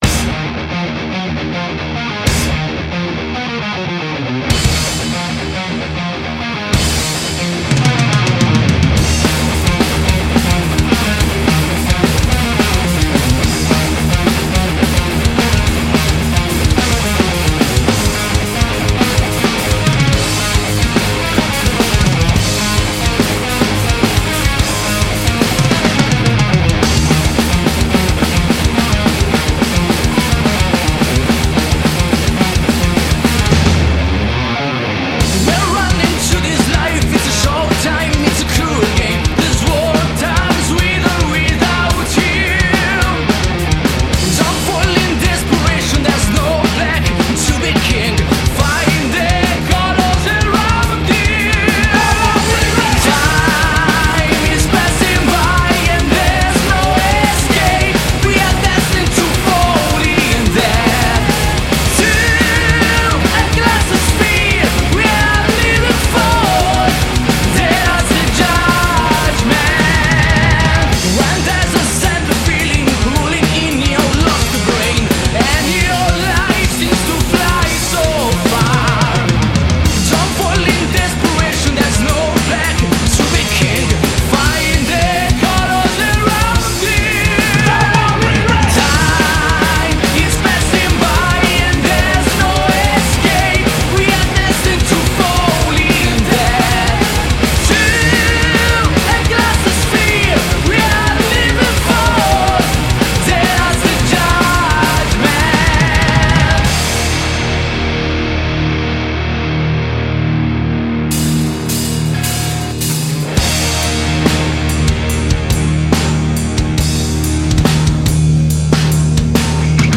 Heavy metal ▸ Heavy metal classico